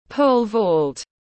Môn nhảy sào tiếng anh gọi là pole vault, phiên âm tiếng anh đọc là /ˈpəʊl vɔːlt/
Pole-vault-.mp3